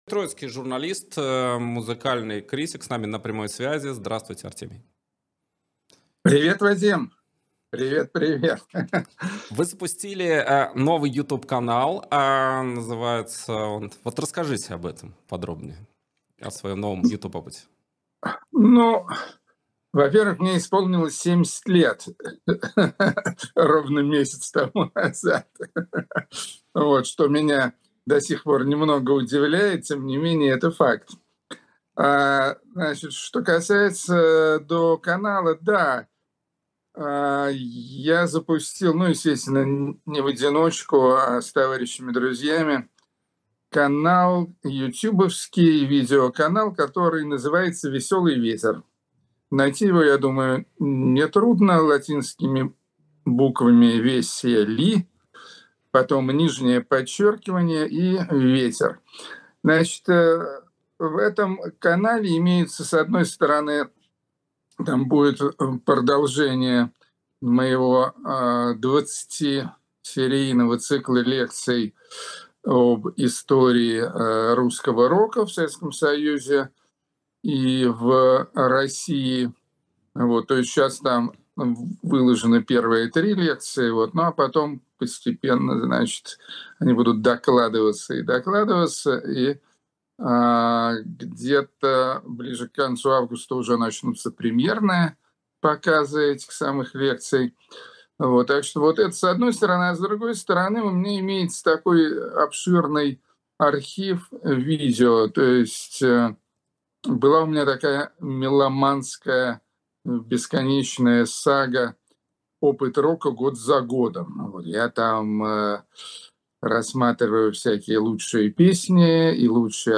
Интервью на канале «И грянул грэм» с Артемием Троицким